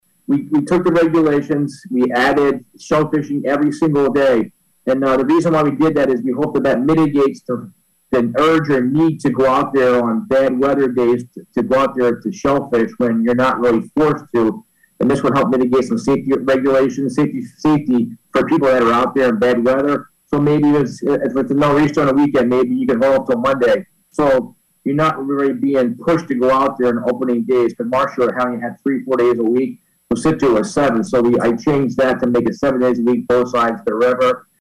Marshfield Harbormaster Mike DiMeo, also the Shellfish Constable in Scituate, says there will be new regulations.